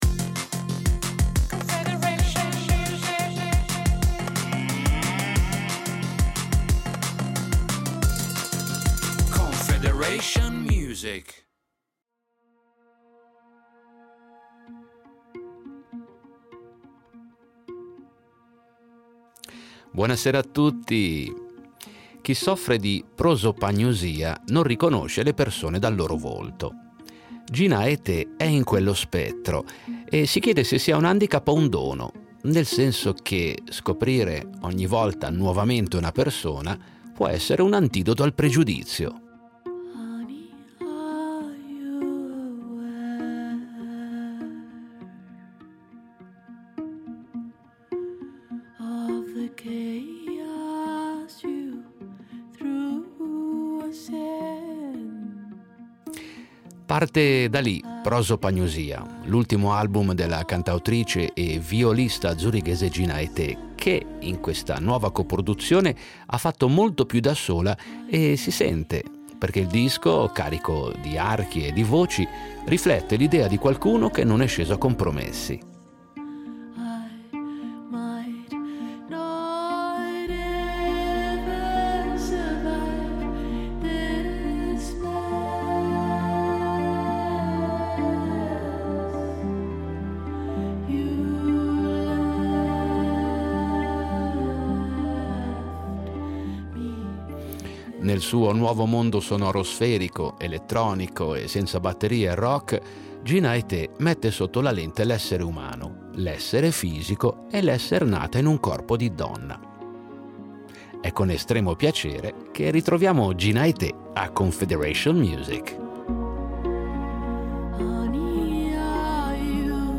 Musica pop
il disco, carico d’archi e di voci, riflette l’idea di qualcuno che non è sceso a compromessi.